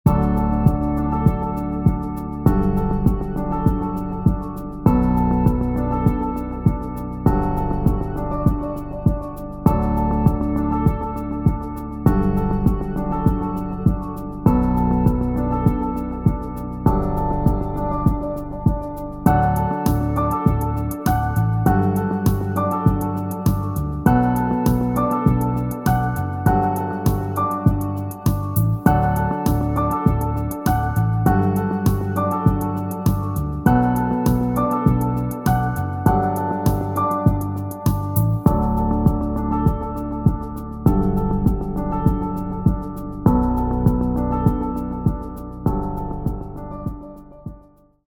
INSTRUMENTAL-WE-STORIE-2.mp3